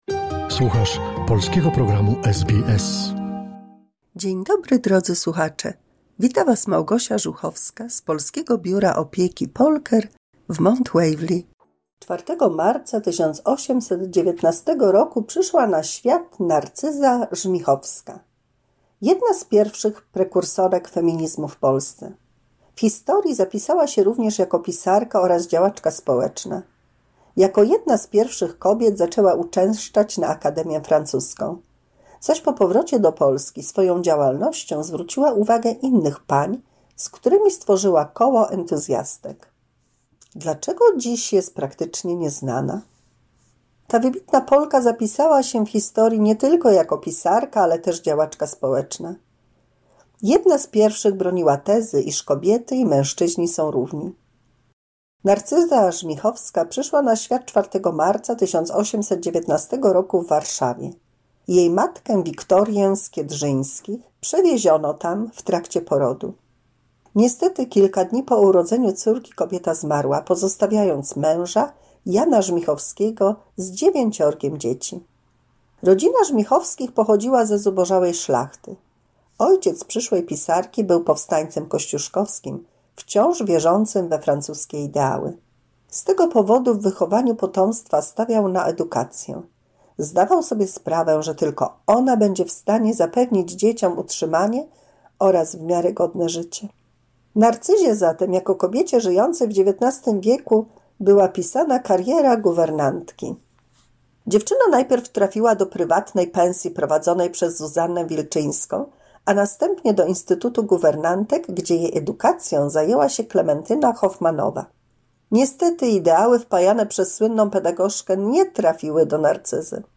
W 214 mini słuchowisku dla polskich seniorów usłyszymy o życiu i twórczości Narcyzy Żmichowskiej i o emancypantkach.